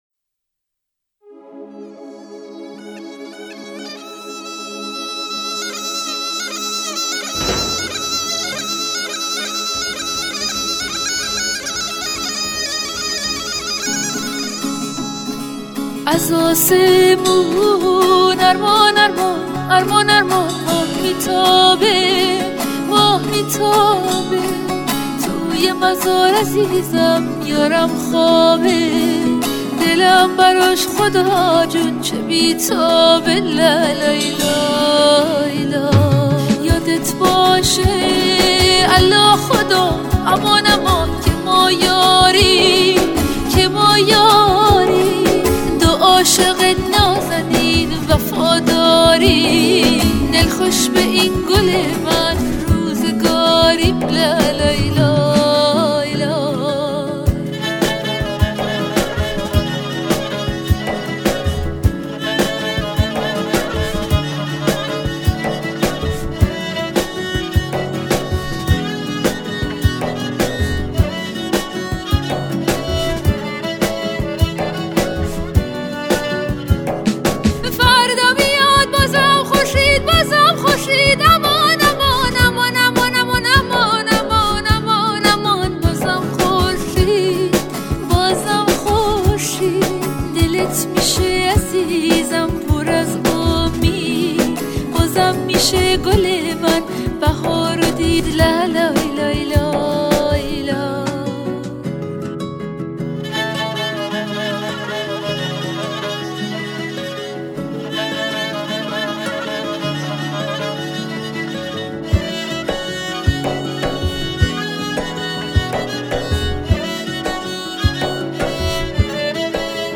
با صدای زن